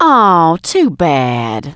piper_kill_04.wav